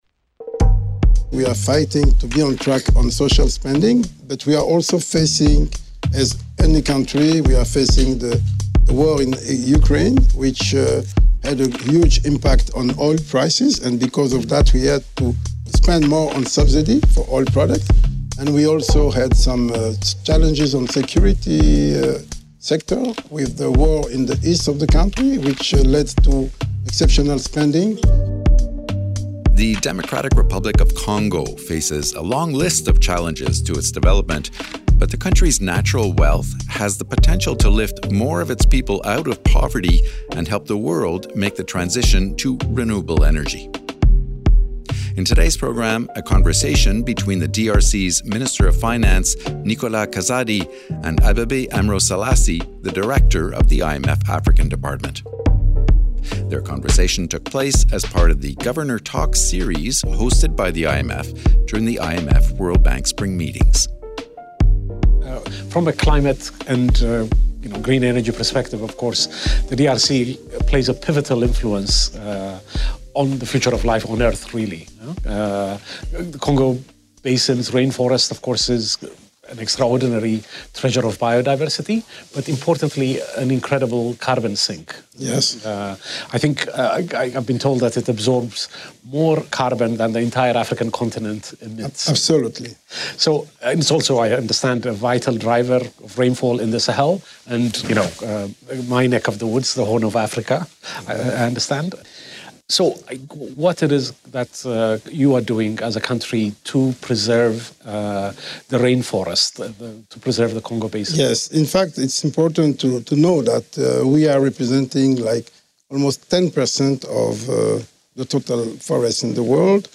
In this podcast, IMF African Department head Abebe Aemro Selassie sits down with DRC’s Minister of Finance, Nicolas Kazadi to discuss the country’s pivotal role in the fight for climate preservation and sustainable development. Their conversation took place as part of the Governor Talks series hosted by the IMF during the IMF-World Bank Spring Meetings.